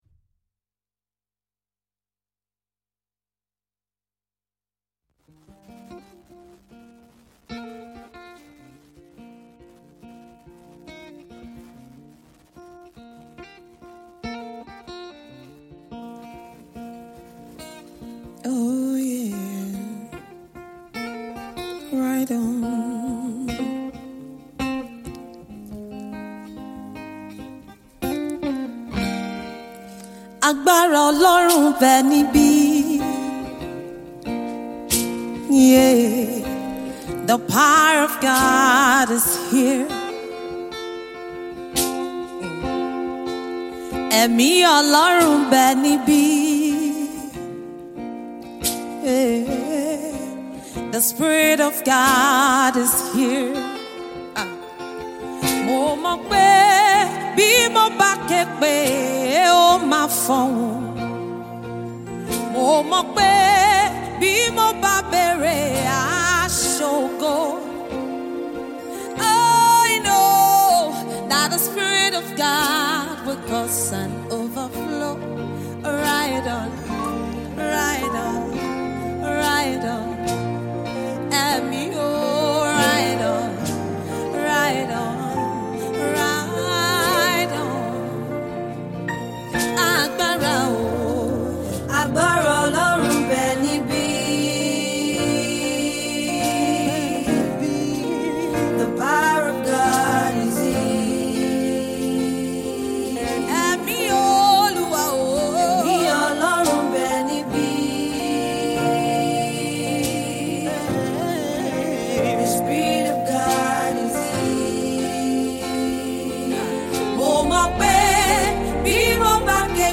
Gospel music
live recorded
This music medley